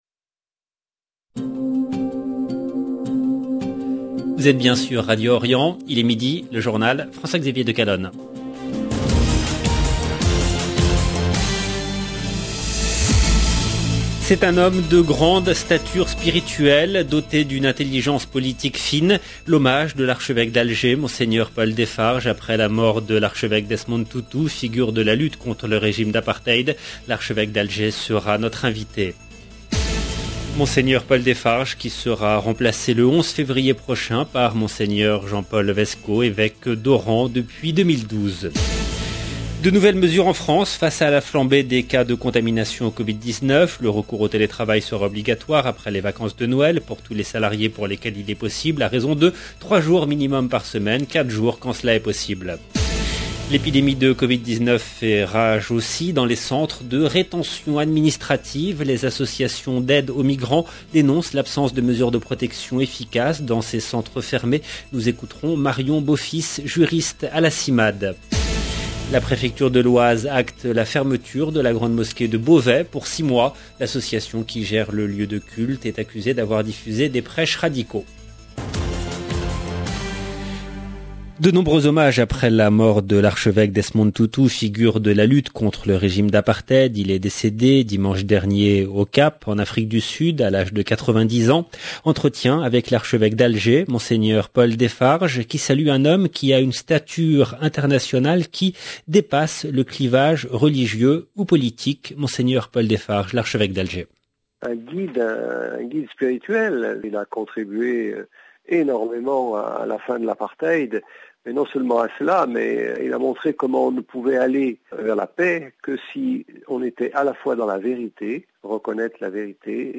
LB JOURNAL EN LANGUE FRANÇAISE
L'Archevêque d’Alger sera notre invité.